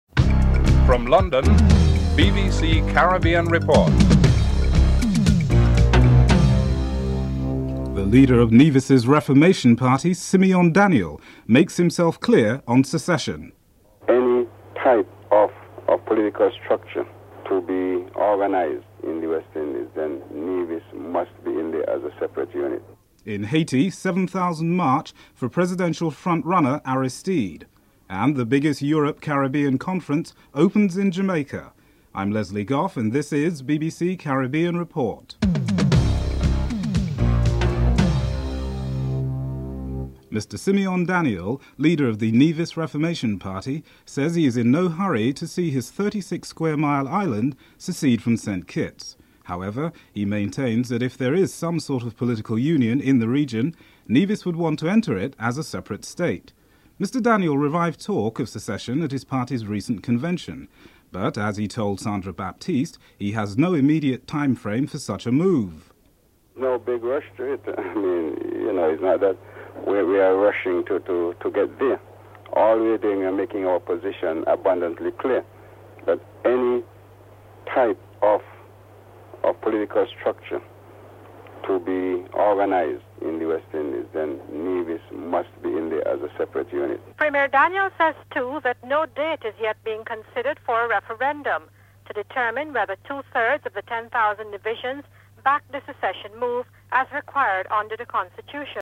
1. Headlines (00:00-00:39)
The report also features the opinions of the man of the street in Nevis on the subject of secession (00:40-05:17)